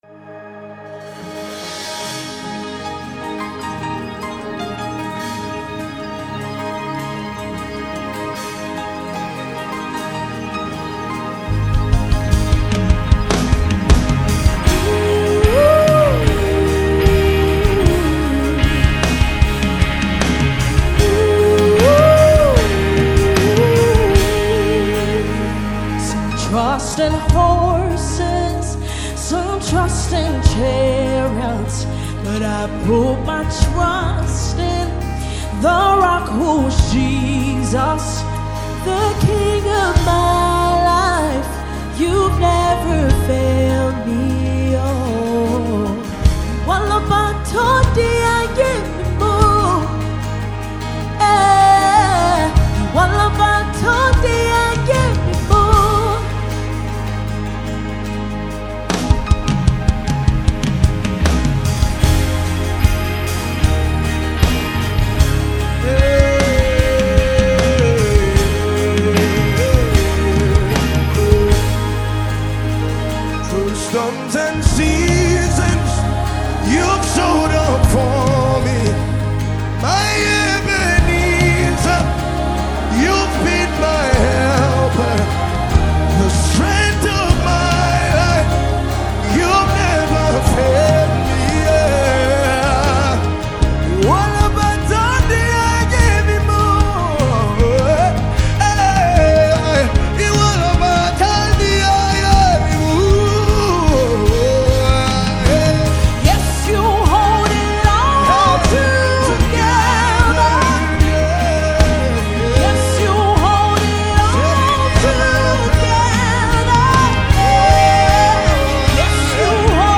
Yoruba Gospel Music